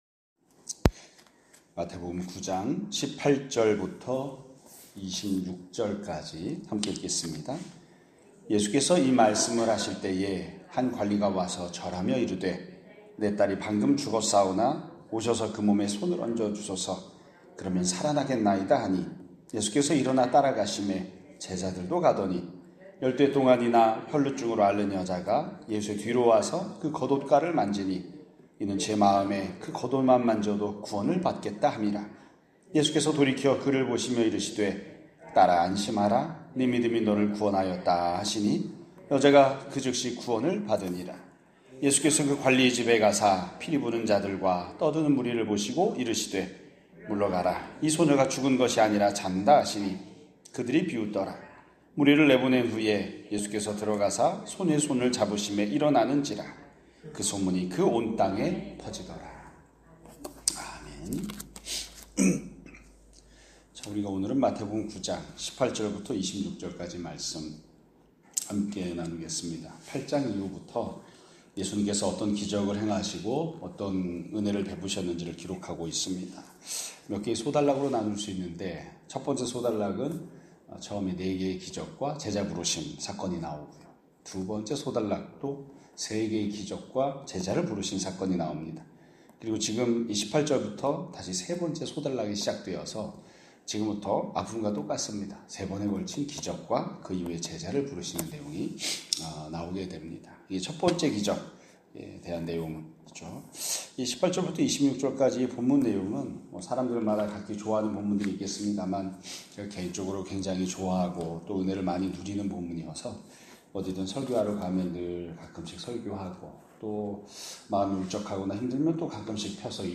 2025년 7월 23일(수요일) <아침예배> 설교입니다.